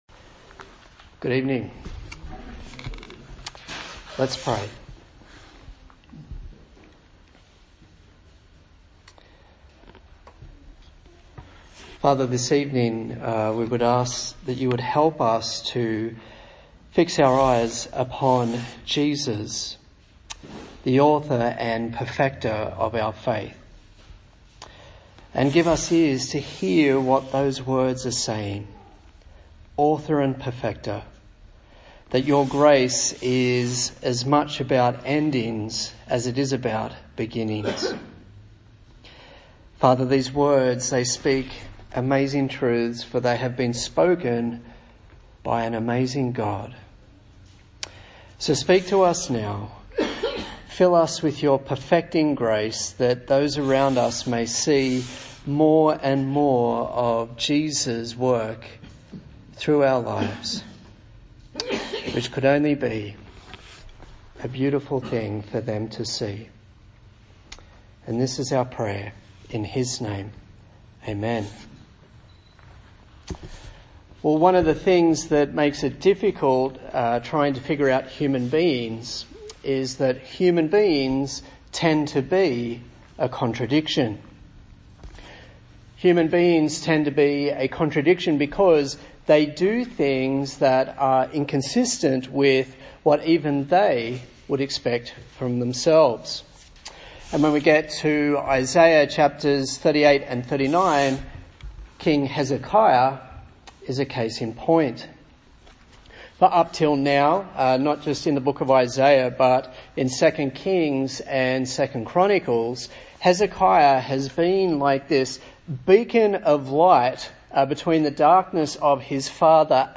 A sermon in the series on the book of Isaiah